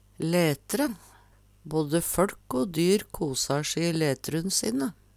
letre - Numedalsmål (en-US)